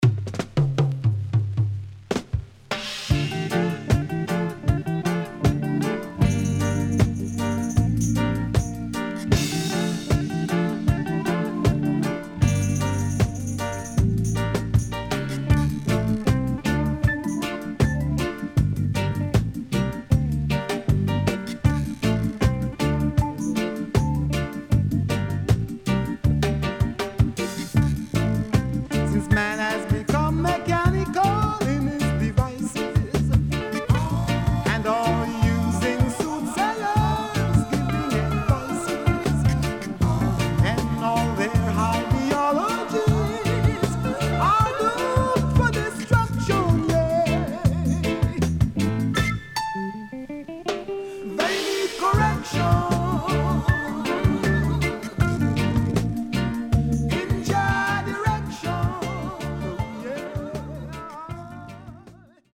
SIDE A:軽いヒスノイズ入りますが良好です。